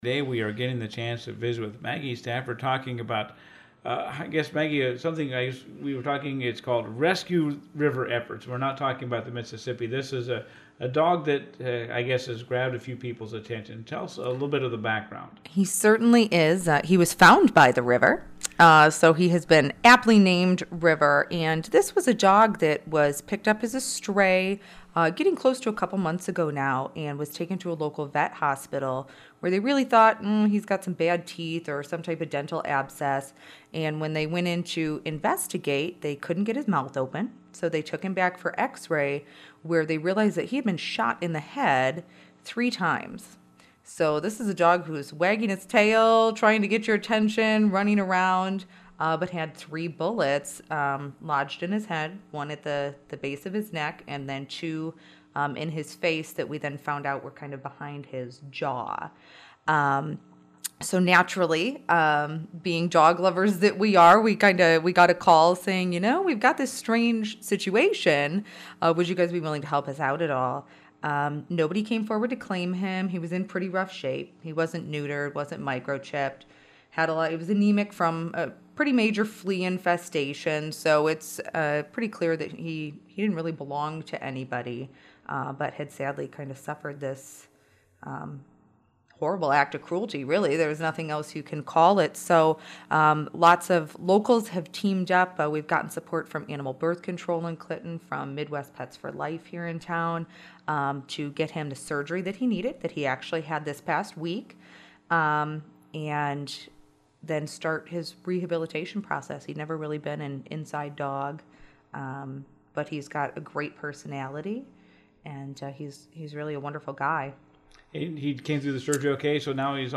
A group of citizens are working to a dog they’ve named River after he was found wandering near the river and had been shot in the head three times. Learn more in this interview about ‘Rescuing River’